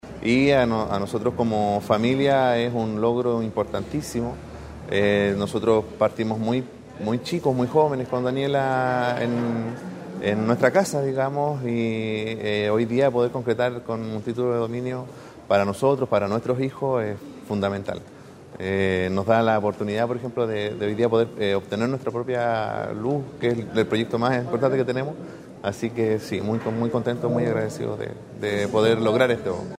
En el salón principal de la casa de la cultura de la comuna de Illapel se llevó a cabo la entrega de 67 títulos de domino a beneficiarios y beneficiarias de la capital provincial, instancia que fue encabezada por el Subsecretario de Bienes Nacionales Sebastián Vergara, quien junto a la Delegada Presidencial provincial de Choapa, Nataly Carvajal, el Seremi de Bienes Nacionales, Marcelo Salazar y el edil comunal, Denis Cortes Aguilera, apreciaron como se cristalizaba el sueño de estas familias illapelinas de ser propietarias del inmueble que habitan.